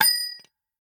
nut_impact_05.ogg